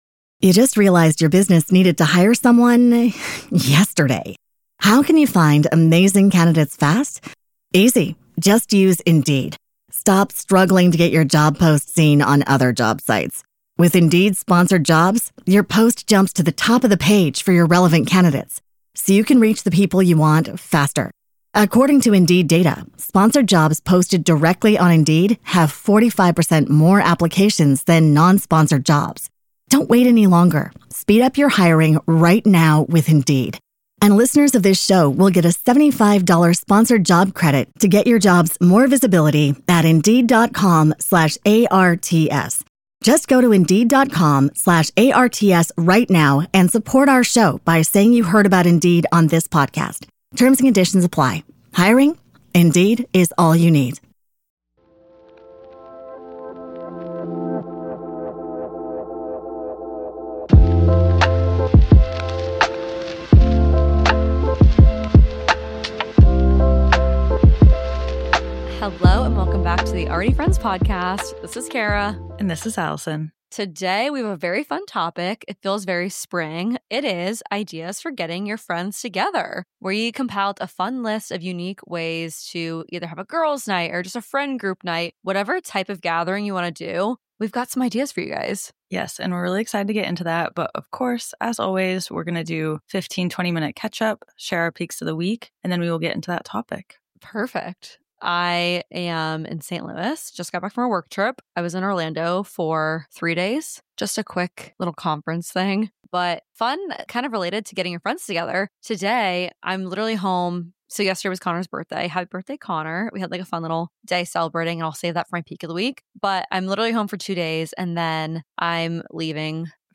Already Friends is your weekly dose of wellness and real talk, designed to help you elevate your well-being one episode at a time. Hosted by two best friends, we explore topics on personal growth, self-care, and building meaningful relationships—all while fostering a community of like-minded listeners around the world.